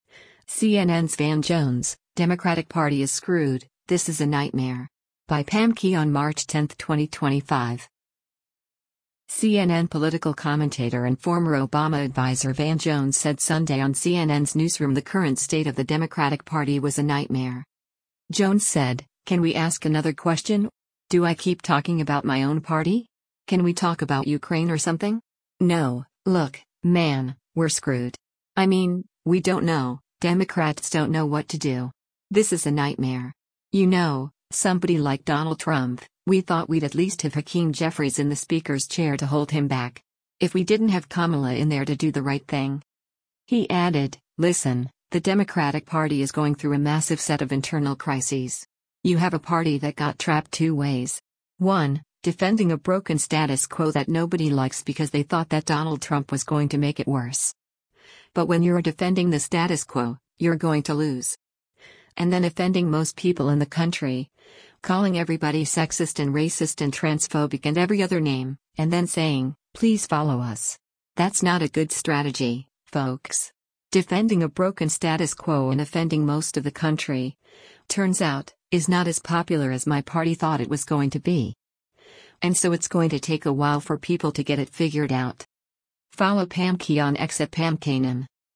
CNN political commentator and former Obama adviser Van Jones said Sunday on CNN’s “Newsroom” the current state of the Democratic Party was a “nightmare.”